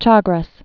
(chägrĕs)